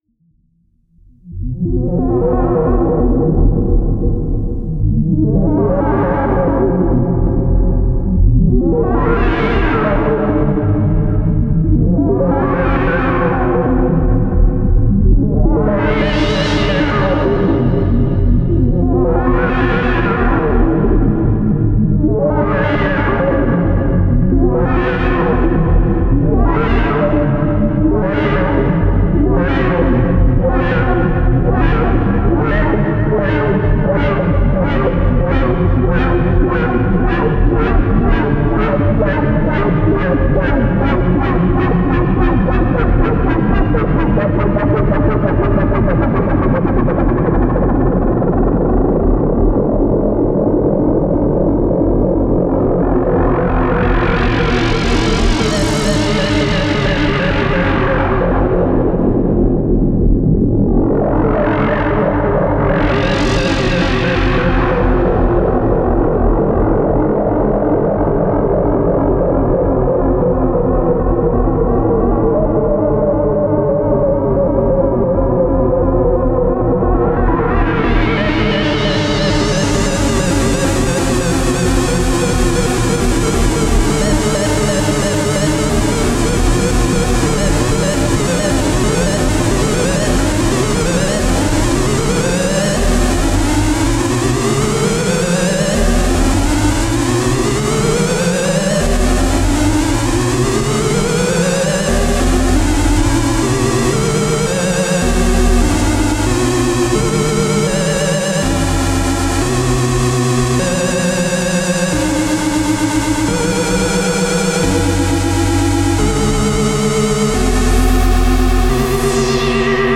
Sound samples, which have been created during a sunday-afternoon session with rsf Kobol Expanders:
Experiments with Stereo Modulation
LP Filter and Pitch of two Kobols are modulated through the mod matrix with multiple LFOs, combined with different operators. LFO settings are tweaked during the sound is playing. Only a single note is played!
mbsidv2_kobol_modulation.mp3